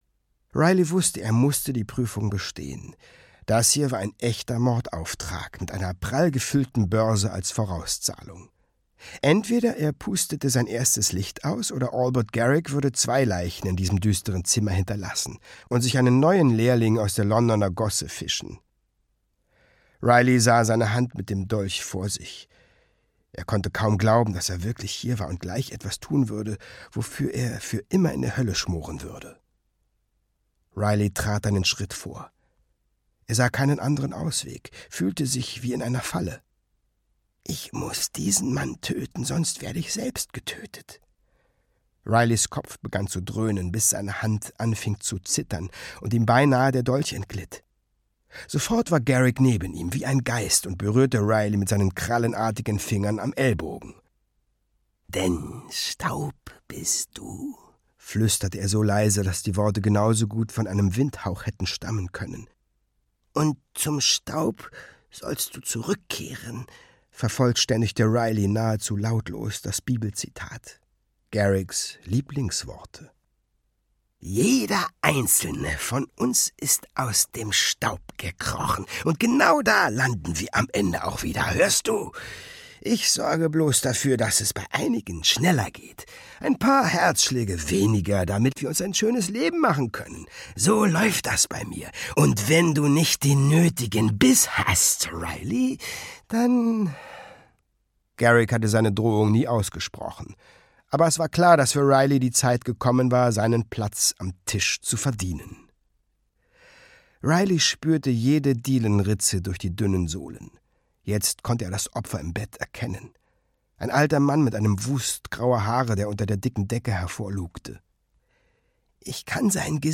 WARP - Der Quantenzauberer - Eoin Colfer - Hörbuch